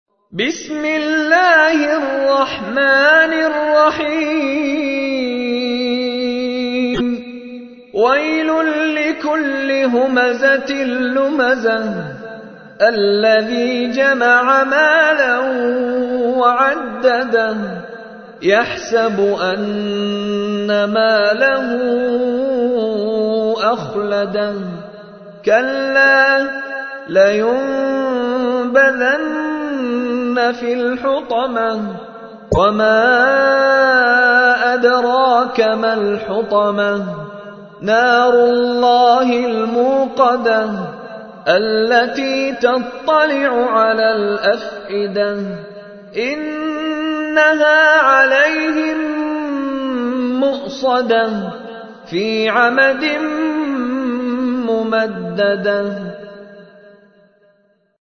تحميل : 104. سورة الهمزة / القارئ مشاري راشد العفاسي / القرآن الكريم / موقع يا حسين